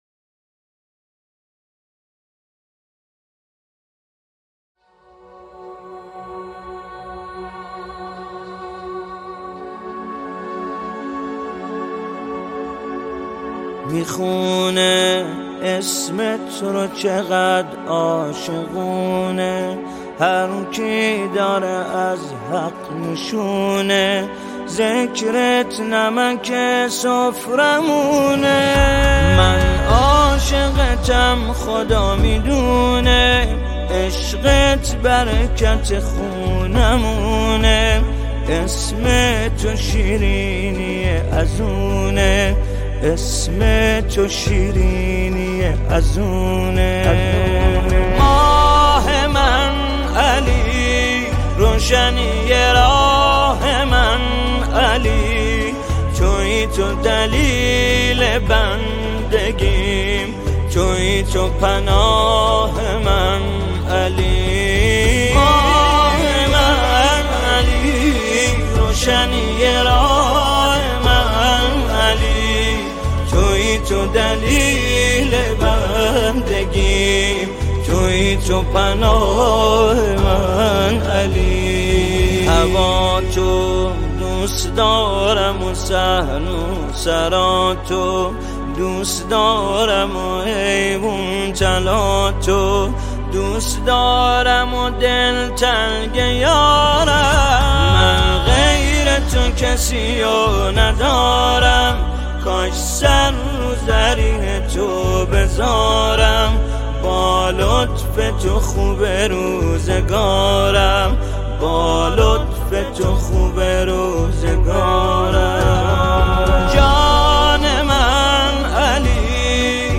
نماهنگ حزین
با نوای دلنشین